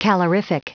Prononciation du mot calorific en anglais (fichier audio)
Prononciation du mot : calorific